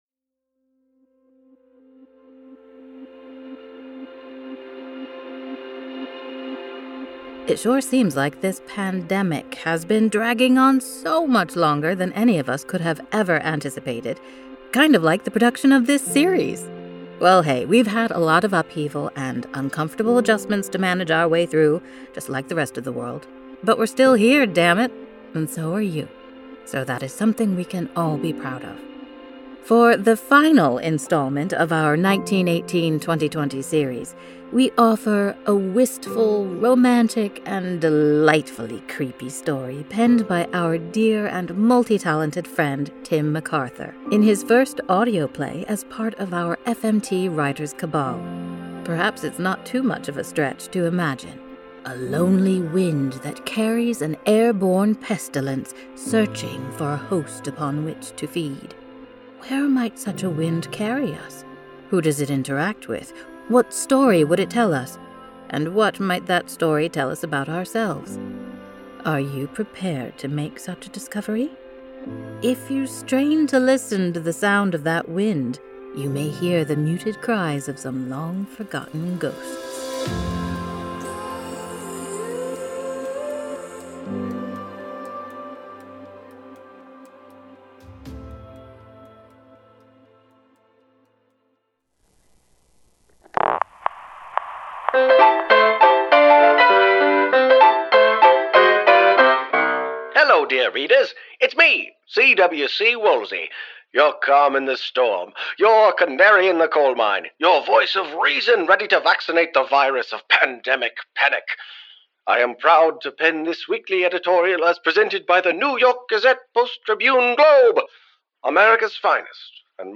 "Facing Time" is the final installment in our pandemic series, 1918/2020, original audio plays inspired by the past and present.